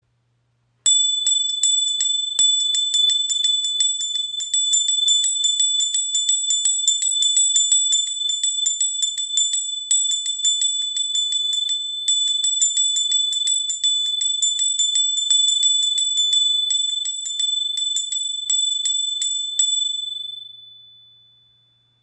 Ihr klarer Klang ist ideal für Meditation und Rituale.
Ein sanftes Schwingen, ein heller, vibrierender Ton – die Handglocke erklingt und erfüllt den Raum mit einer spürbaren Energie.
• Material: Messing
• Klang: Klar und rein